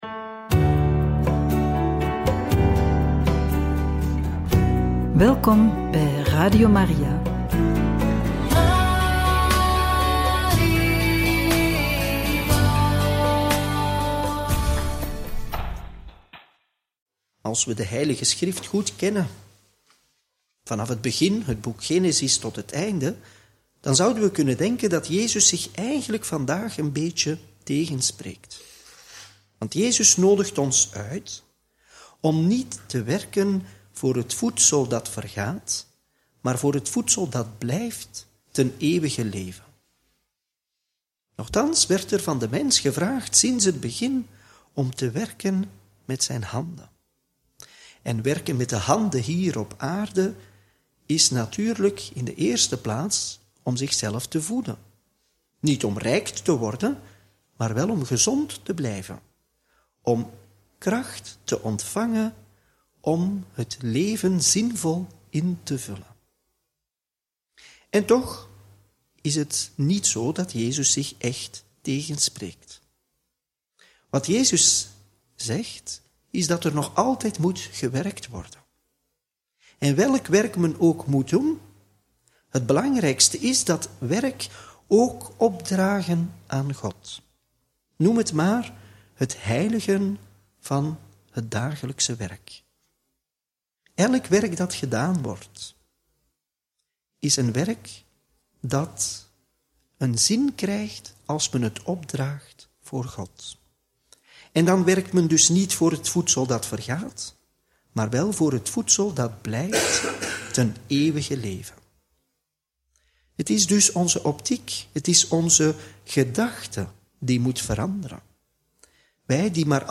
Homilie bij het Evangelie van maandag 5 mei 2025 – Joh. 6, 22-29